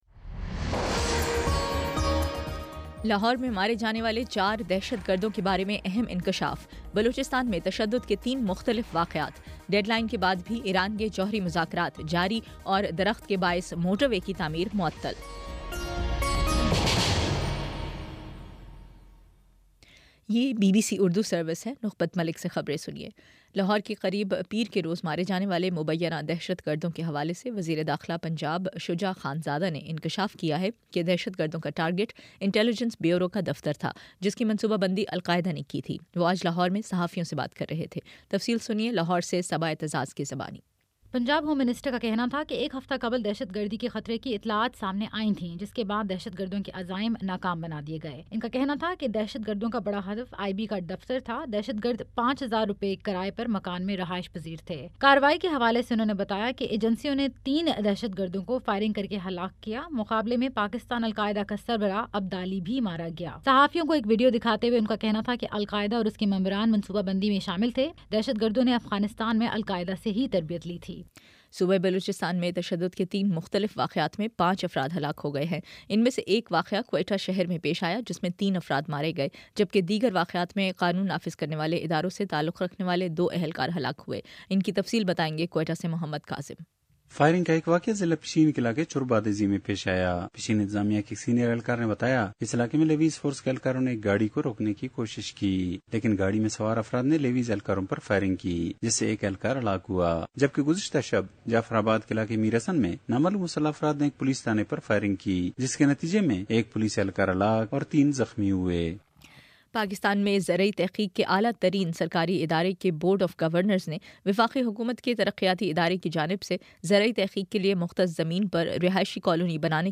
جولائی 1: شام سات بجے کا نیوز بُلیٹن